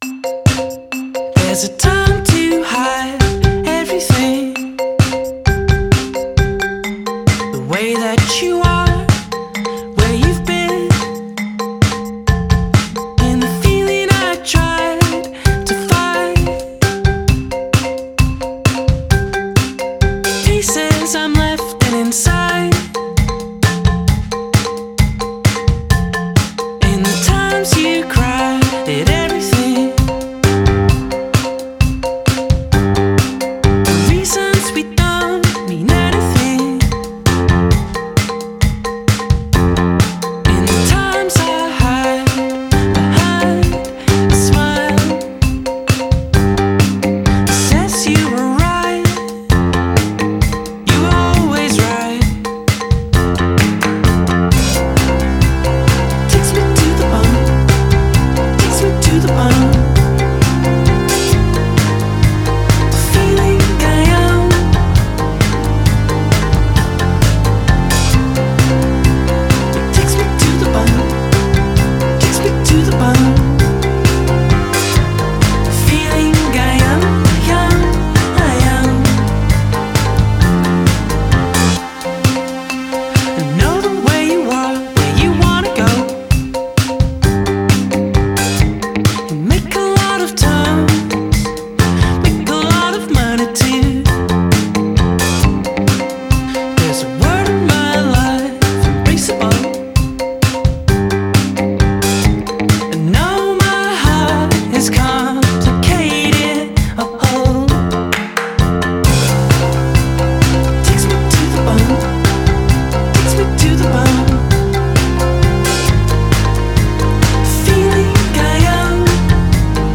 pop personal con aires retro